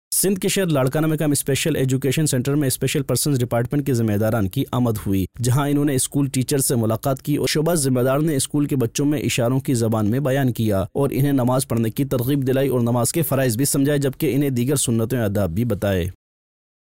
News Clips Urdu - 28 February 2023 - Special Education Center Main Zimadaran Ki School Teachers Say Mulaqat Mar 7, 2023 MP3 MP4 MP3 Share نیوز کلپس اردو - 28 فروری 2023 - اسپیشل ایجوکیشن سینٹر میں ذمہ داران کی اسکول ٹیچرزسےملاقات